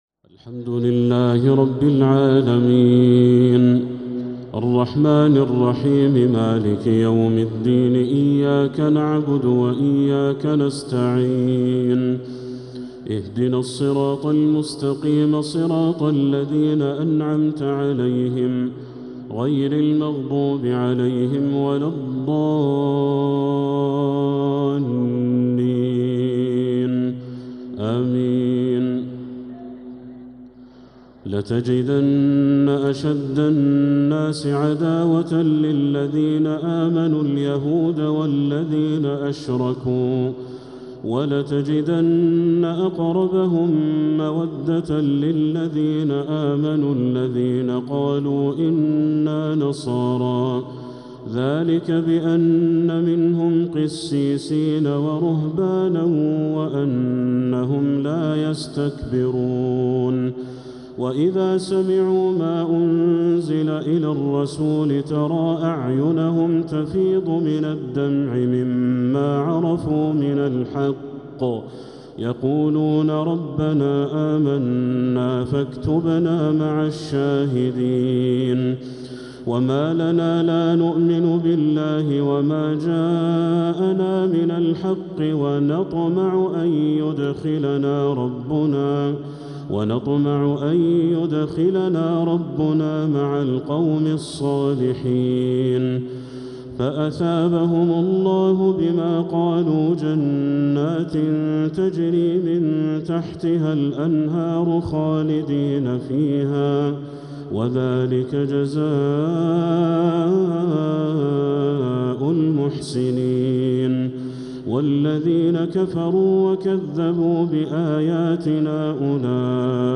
تراويح ليلة 9 رمضان 1446هـ من سورتي المائدة {82-120} و الأنعام {1-20} | Taraweeh 9th night Ramadan 1446H Surat Al-Ma'idah and Al-Ana'am > تراويح الحرم المكي عام 1446 🕋 > التراويح - تلاوات الحرمين